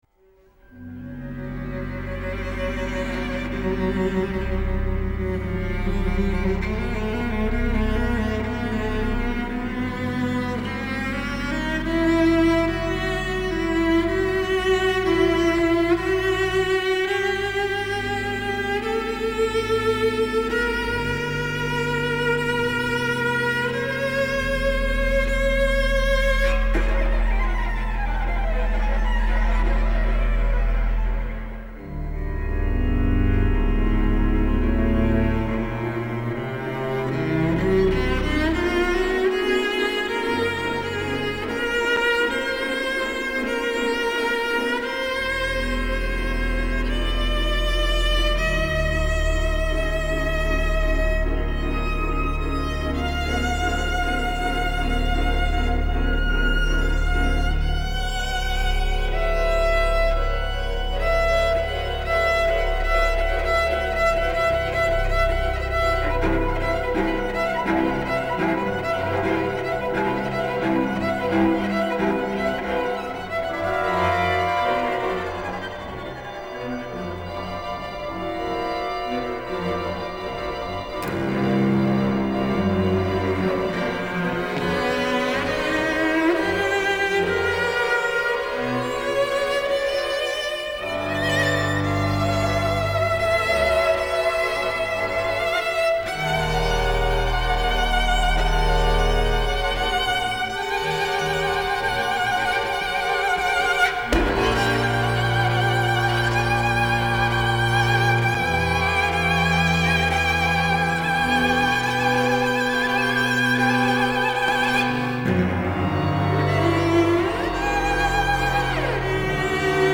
sellolle ja uruille